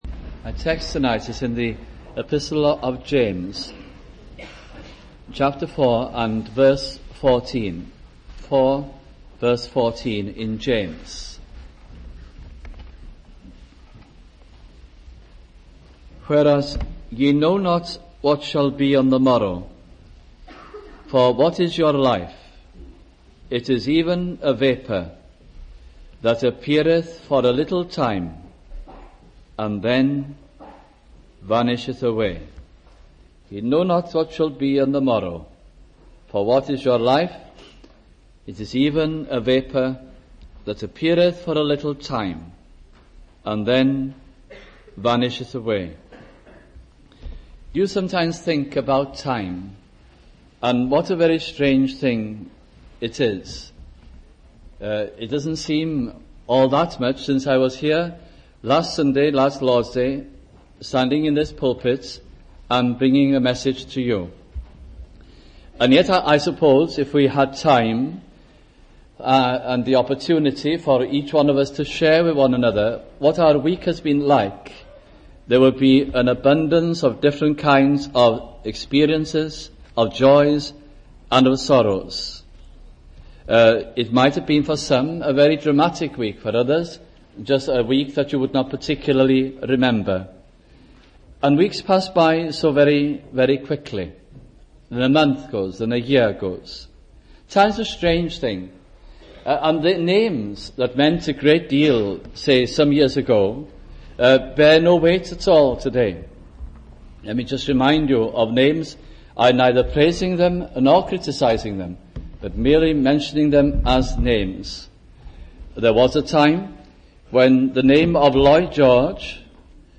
» James Gospel Sermons